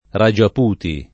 ragiaputi [ ra J ap 2 ti ] → rājpūt